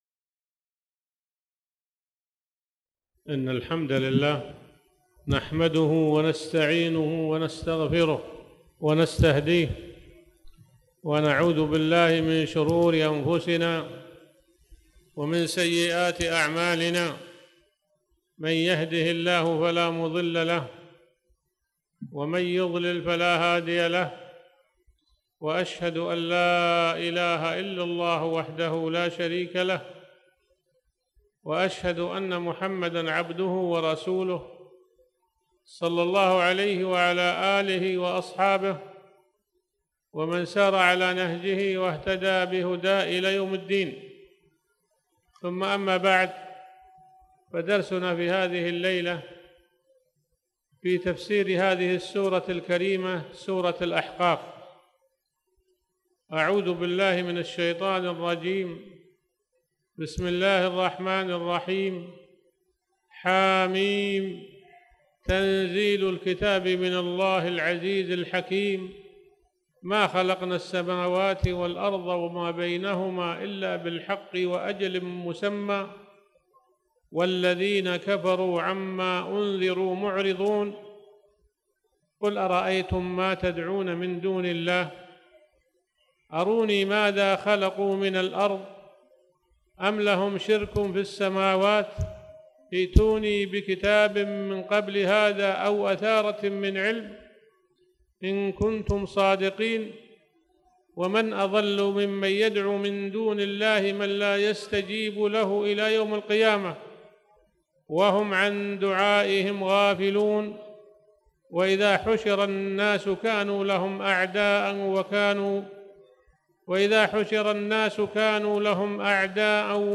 تاريخ النشر ١٧ جمادى الآخرة ١٤٣٩ هـ المكان: المسجد الحرام الشيخ